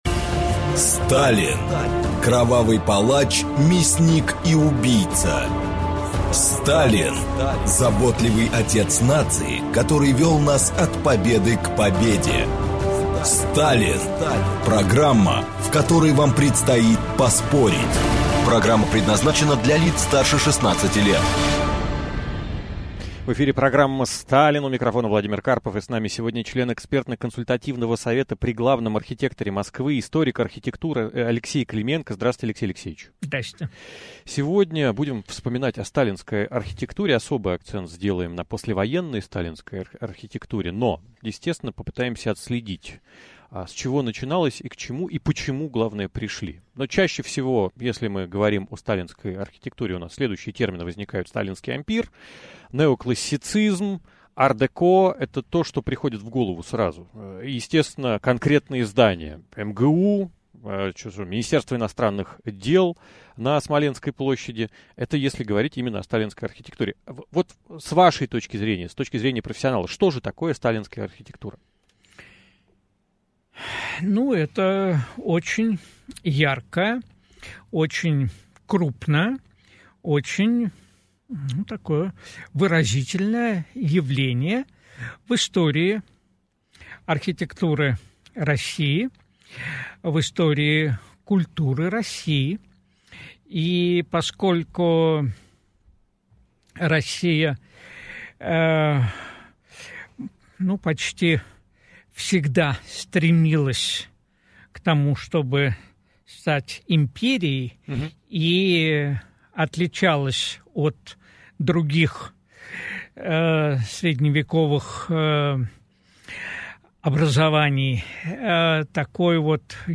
Аудиокнига Сталинская послевоенная архитектура | Библиотека аудиокниг
Прослушать и бесплатно скачать фрагмент аудиокниги